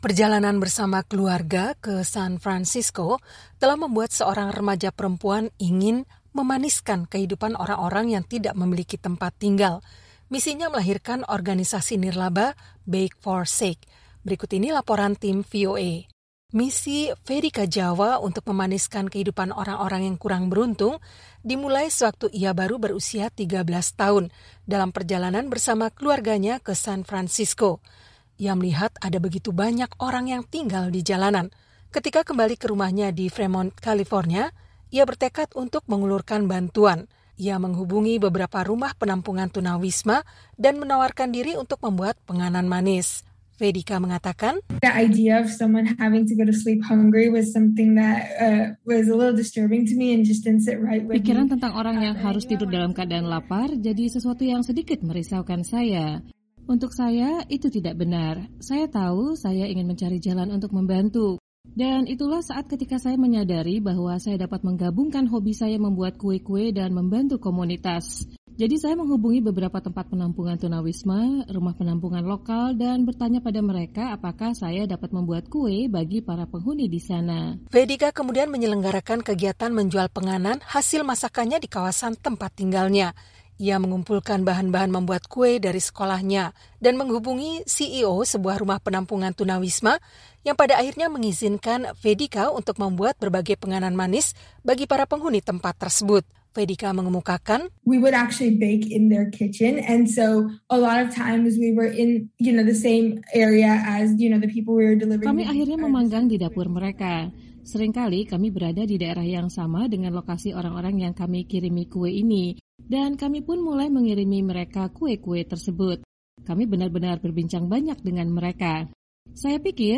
Misinya itu kemudian melahirkan organisasi nirlaba Bake4Sake. Berikut ini laporan tim VOA.